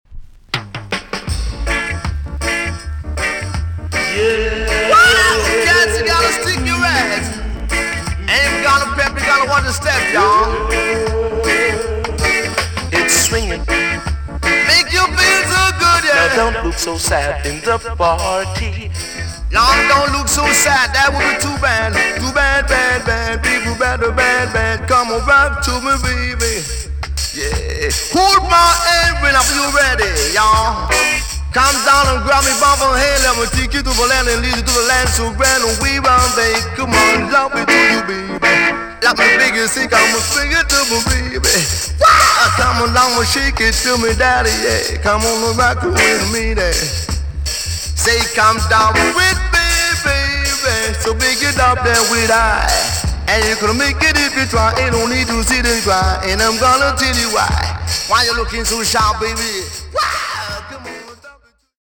A.SIDE EX- 音はキレイです。